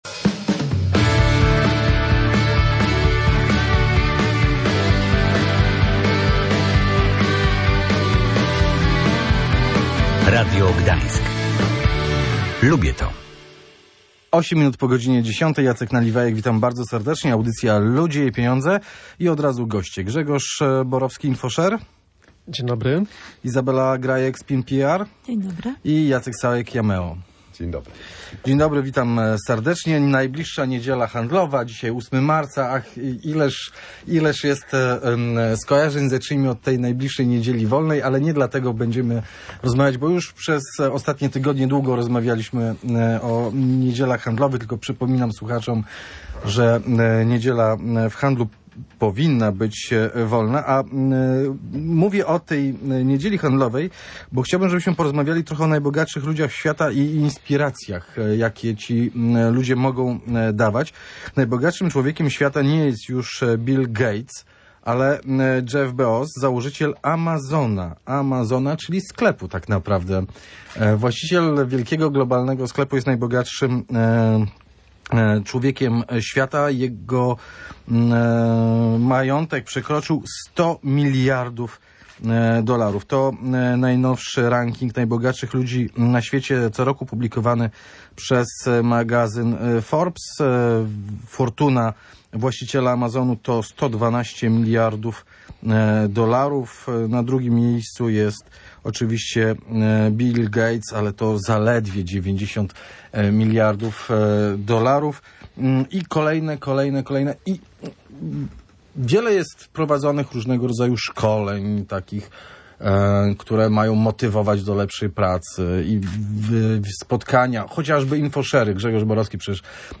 Goście audycji dyskutowali o tym, czy bogactwo jest „cechą” inspirującą innych. Zwracali uwagę na to, że zamożność nie równa się z byciem człowiekiem sukcesu – kimś, kto sam dochodzi czegoś niezwykłego.